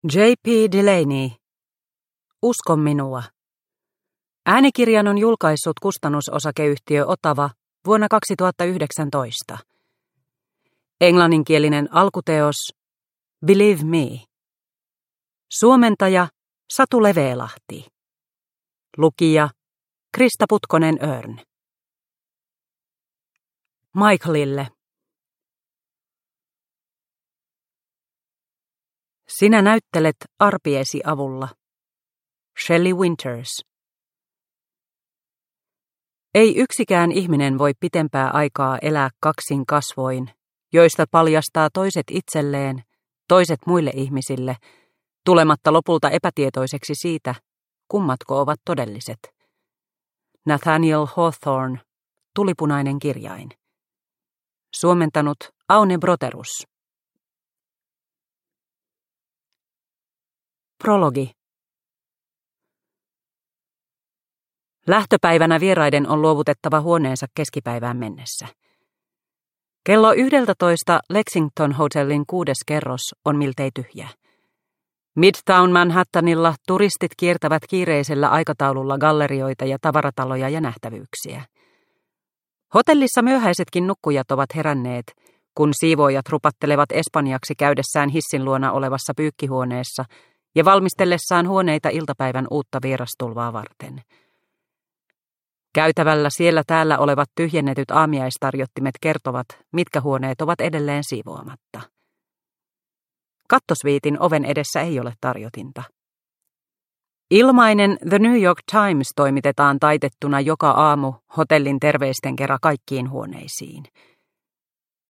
Usko minua – Ljudbok – Laddas ner